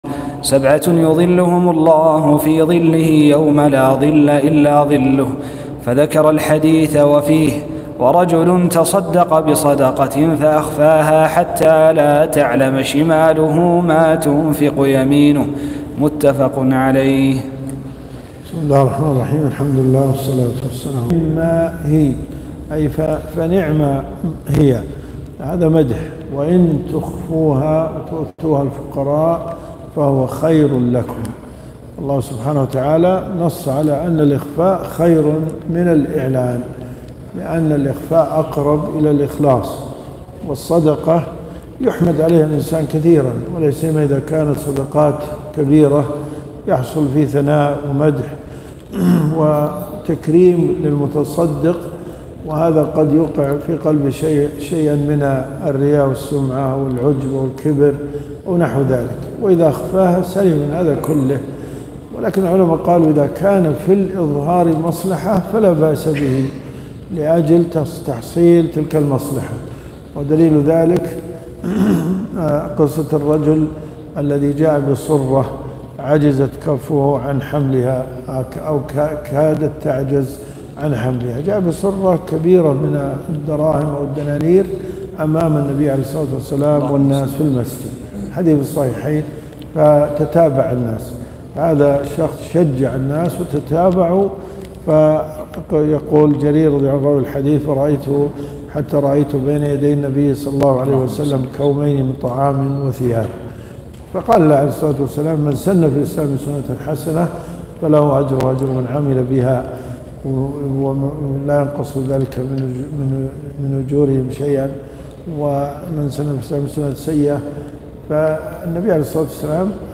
دروس صوتيه ومرئية تقام في جامع الحمدان بالرياض
الرياض . حي العارض . جامع عبدالله بن ناصر المهيني . 1445 + 1446 .